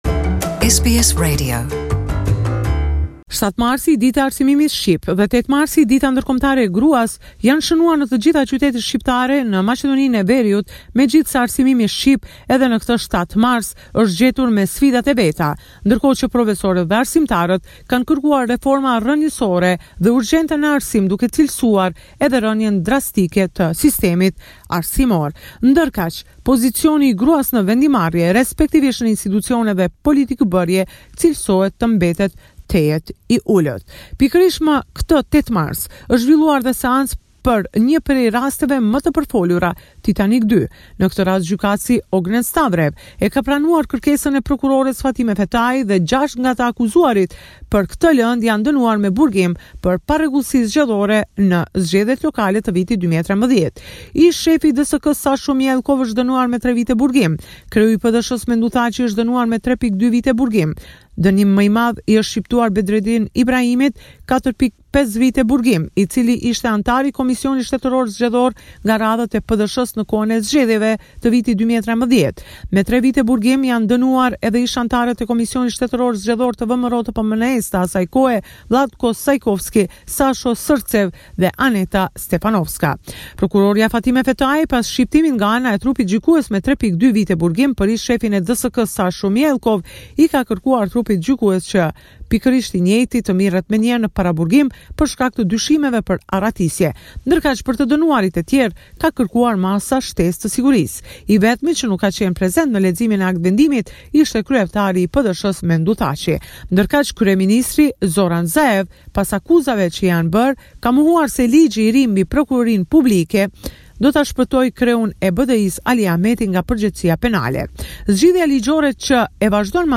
This is a report summarising the latest developments in news and current affairs in Macedonia.